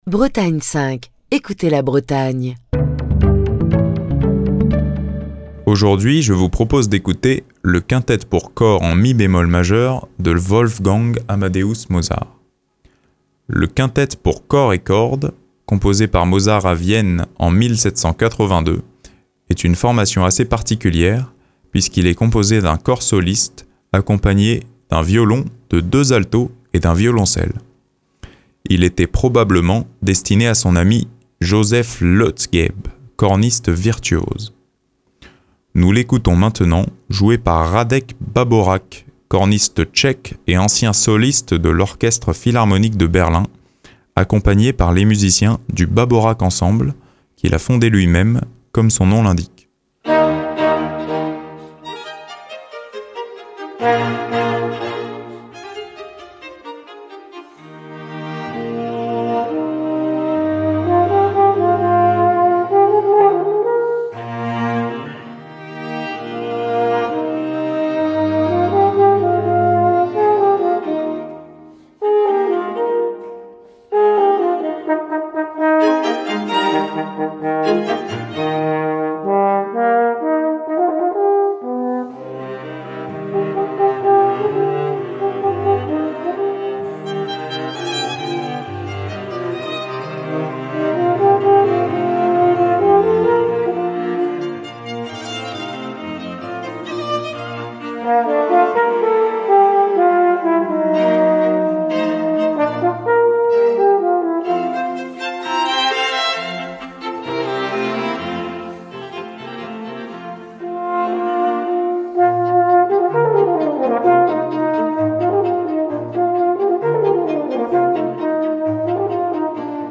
Émission du 10 juin 2024. Le Quintette pour cor et cordes en mi bémol majeur, composé par Mozart à Vienne en 1782, est une formation assez particulière puisqu’il inclut un cor soliste, accompagné d’un violon, de deux altos et d’un violoncelle.
Nous l’écoutons maintenant, joué par Radek Baborak, corniste tchèque et ancien soliste de l’Orchestre Philharmonique de Berlin, accompagné par les musiciens du Baborak Ensemble, qu’il a fondé lui-même, comme son nom l’indique.
Le premier mouvement du Quintette pour cor et cordes de Mozart, interprété par le corniste Radek Baborak et le Baborak Ensemble.